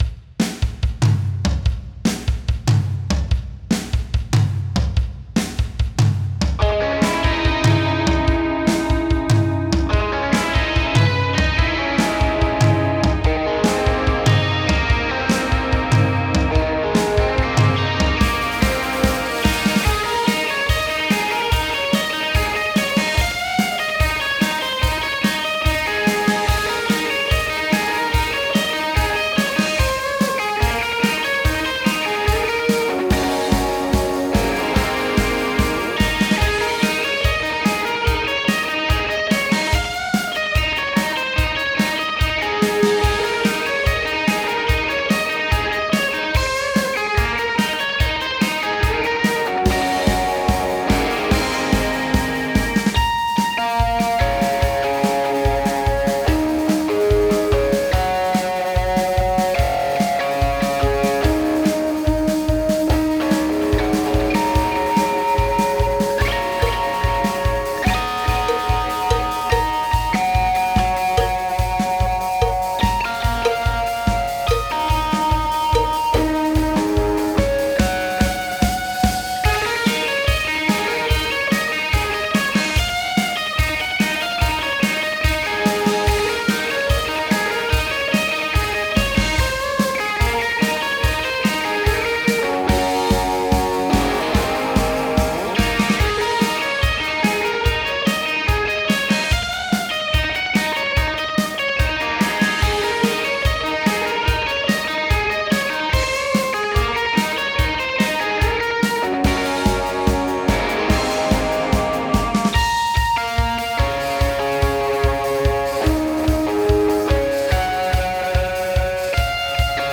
BPM : 145
Tuning : Eb
Without vocals
Based on the studio and Dortmund live version